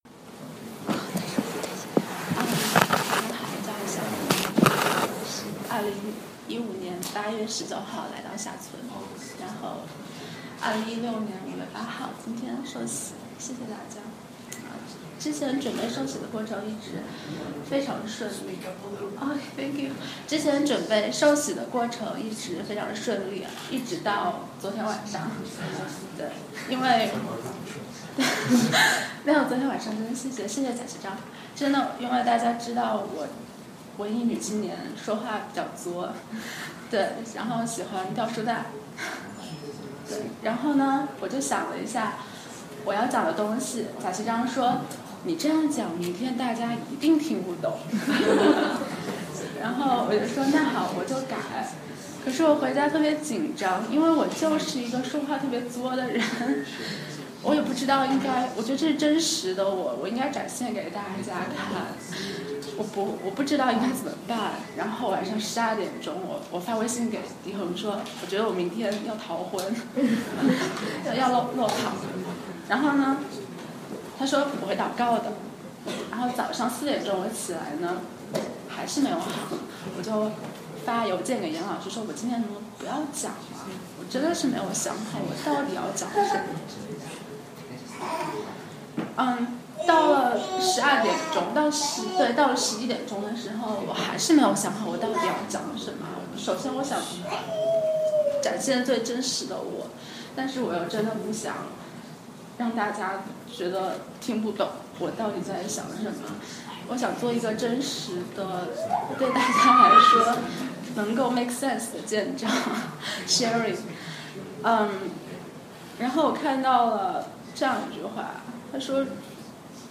受洗見證